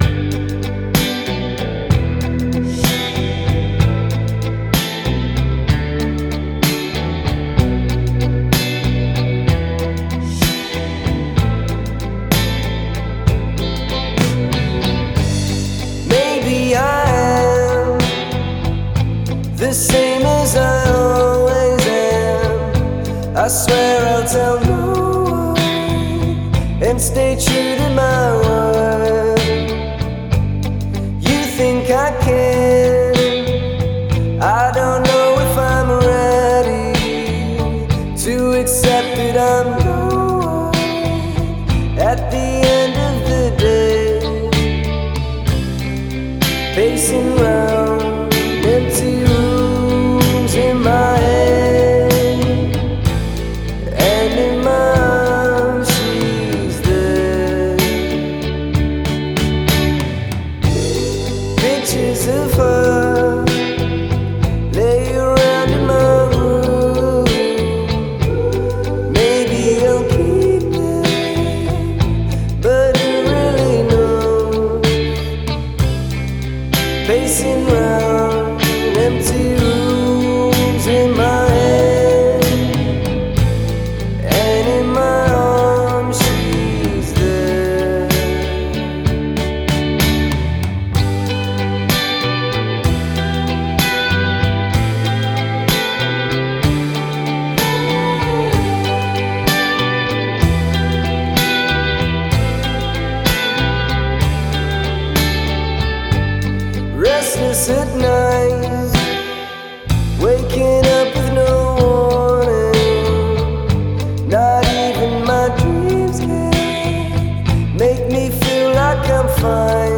sound like an early 1960s British beat group
airy ballad style